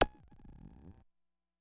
Yamaha CS30 L Velocity Click " Yamaha CS30 L Velocity Click F4 ( Velocity Click6621)
标签： MIDI网速度11 F4 MIDI音符-66 雅马哈-CS-30L 合成器 单票据 多重采样
声道立体声